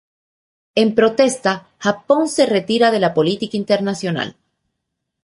Pronúnciase como (IPA)
/pɾoˈtesta/